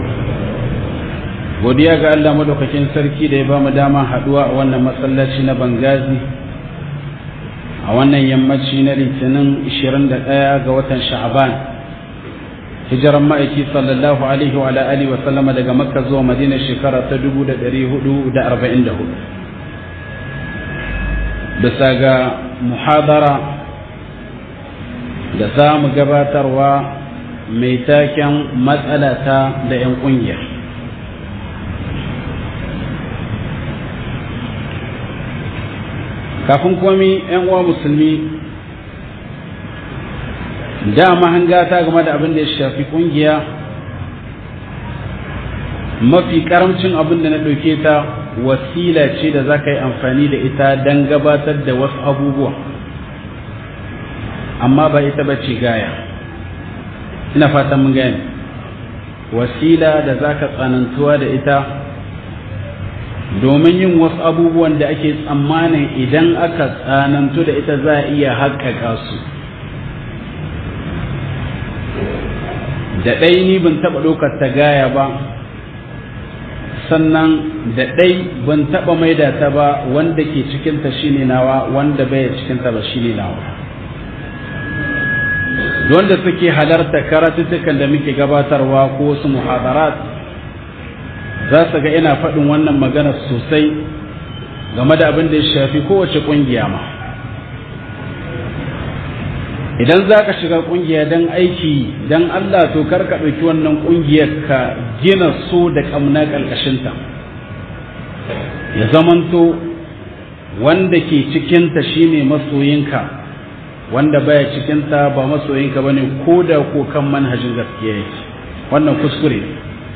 matsala ta da in kungiyoyin (1)~1 - MUHADARA